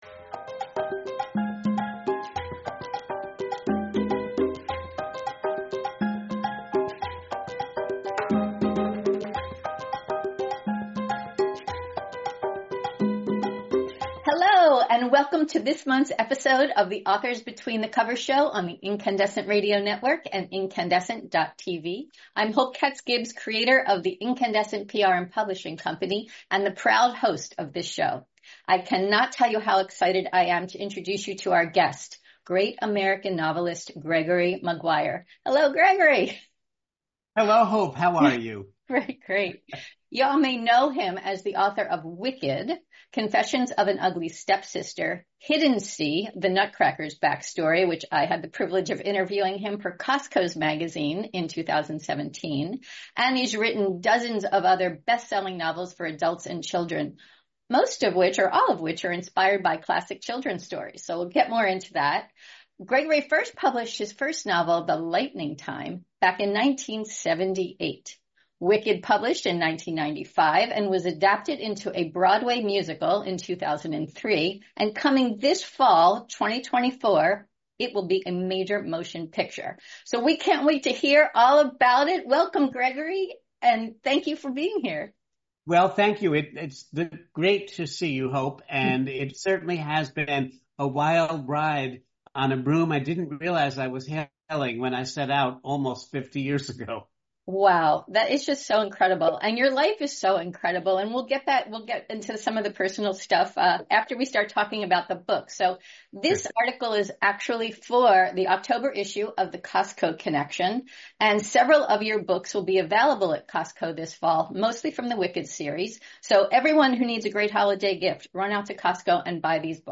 This month on the Authors Between the Covers show: Gregory Maguire, author, “Wicked”